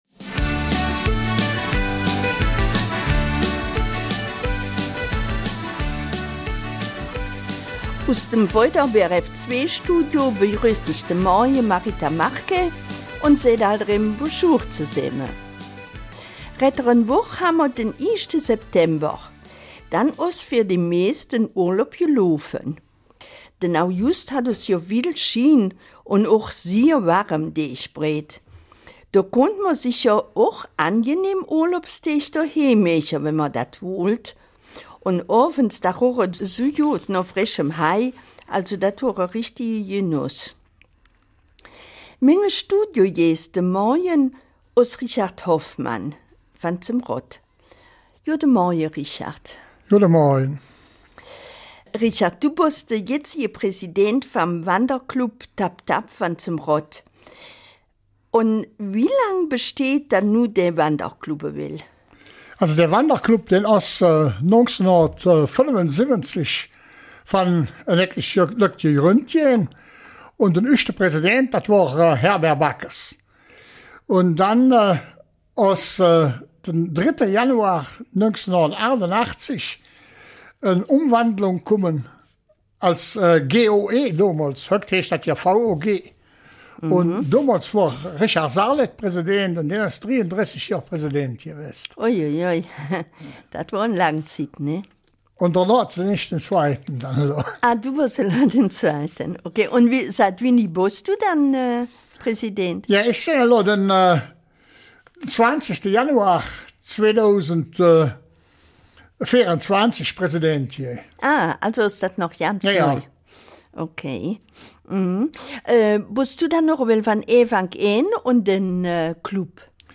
Eifeler Mundart: Wanderclub Tapp-Tapp Rodt